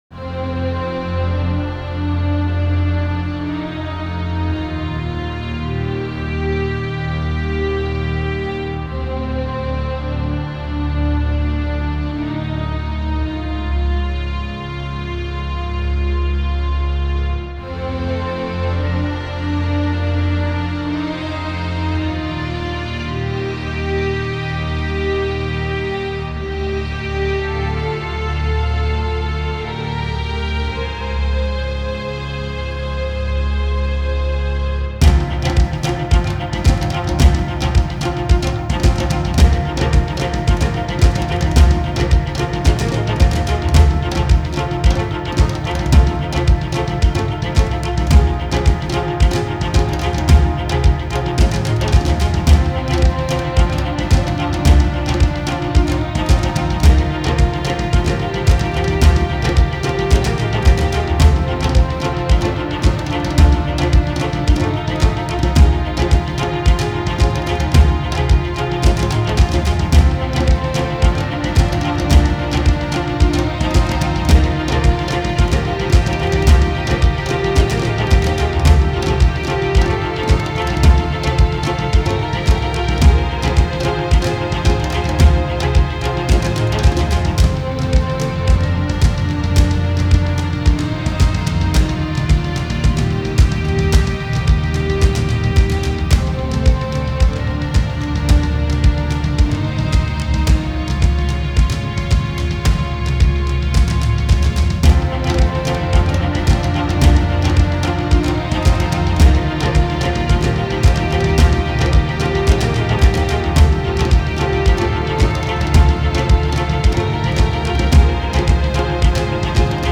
Hordes with drums dance higher into the summit